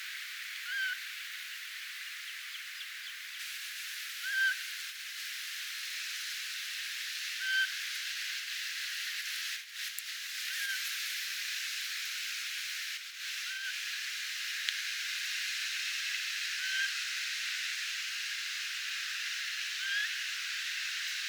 kilpikanan poikasen ääntelyä
Muistuttaa yllättävän paljon varpuspöllön ääntelyä.
kilpikanen_poikasen_aanta_muistuttaa_hieman_varpuspolloa.mp3